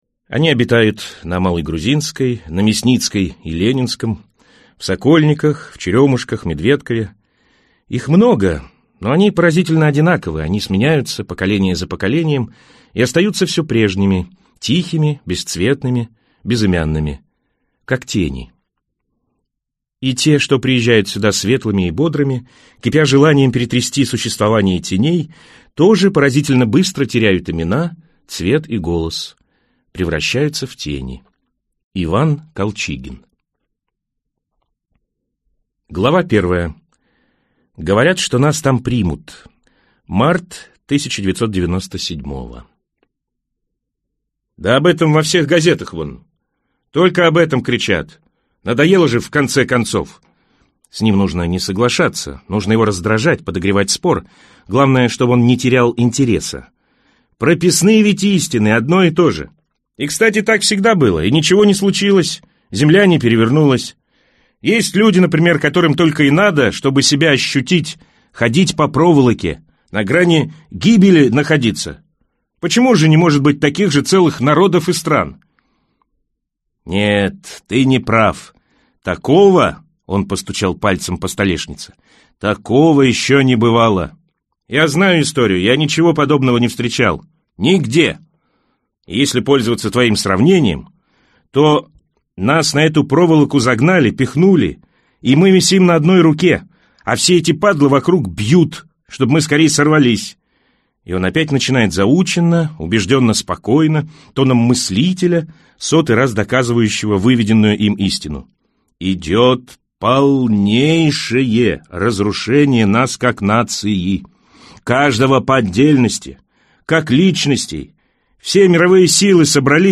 Аудиокнига Московские тени | Библиотека аудиокниг